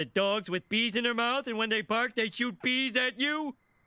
the-dogs-with-bees-in-their-mouth-audiotrimmer_gY0lFZ0.mp3